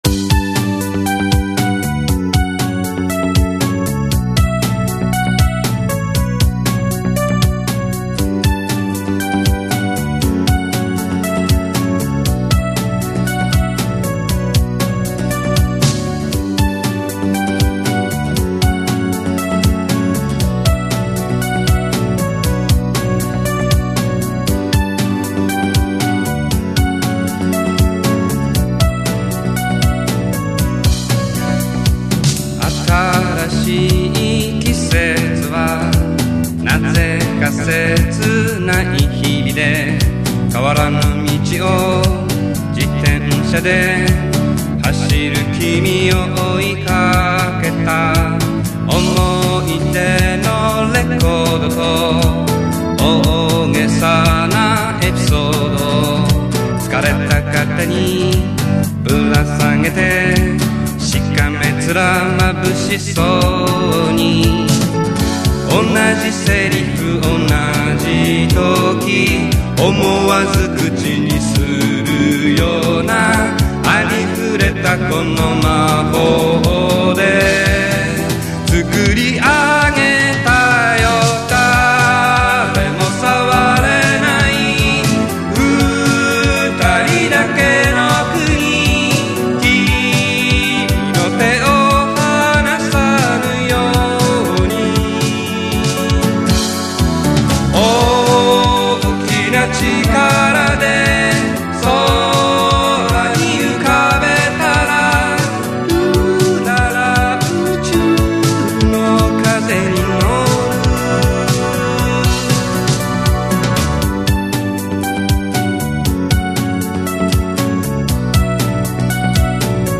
「Ｃａｋｅｗａｌｋ」で多重録音および編集、ボーカルマイクにはエフェクターを通している